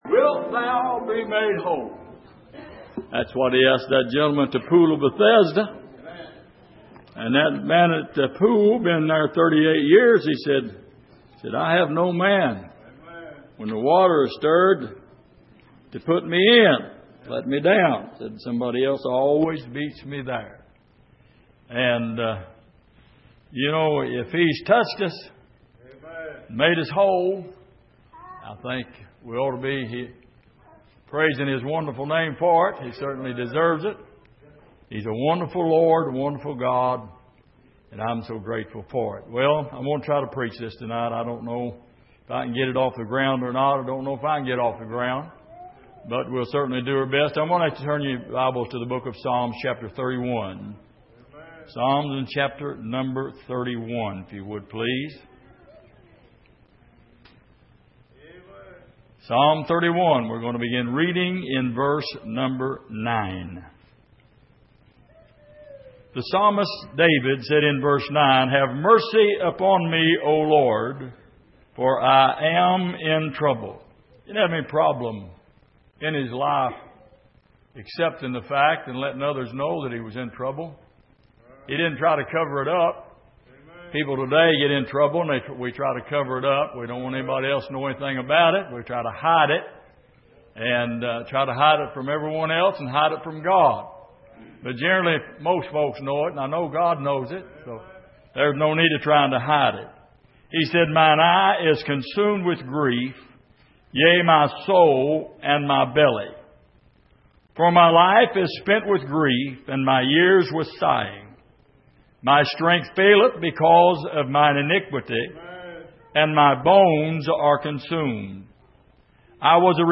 Passage: Psalm 31:9-18 Service: Sunday Evening